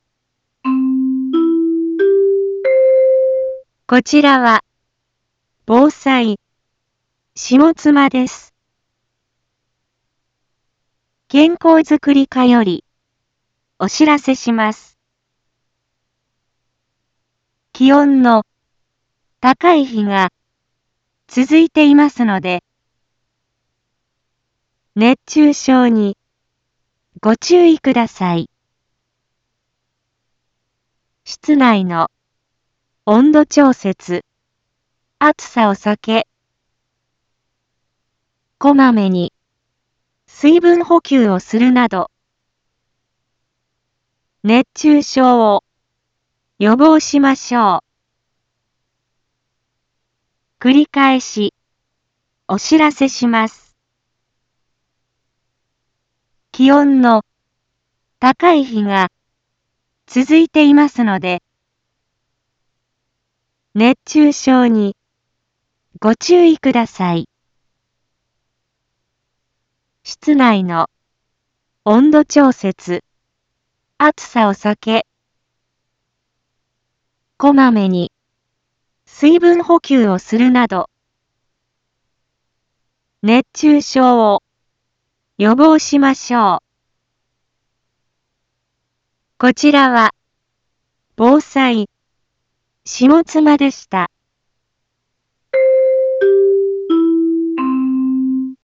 一般放送情報
Back Home 一般放送情報 音声放送 再生 一般放送情報 登録日時：2023-08-03 11:01:42 タイトル：熱中症注意のお知らせ インフォメーション：こちらは、防災、下妻です。